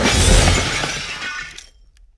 crash1.ogg